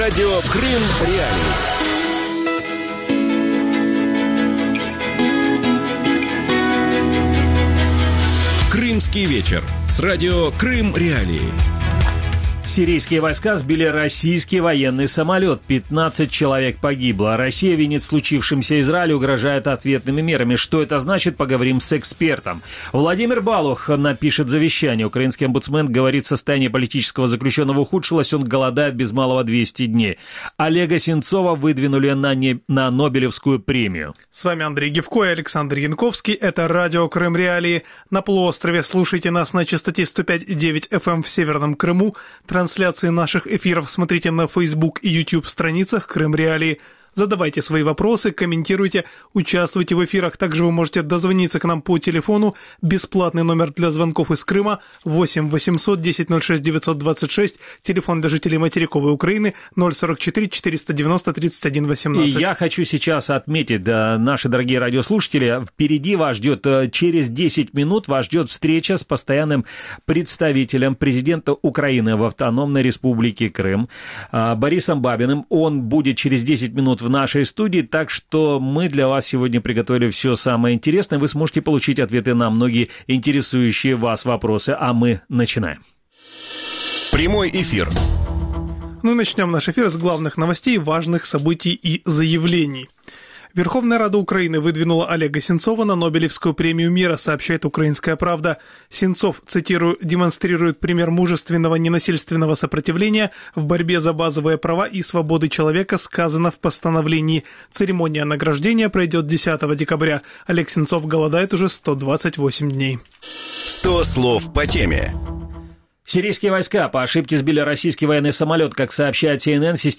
в студии Радио Крым.Реалии в ток-шоу «Крымский вечер»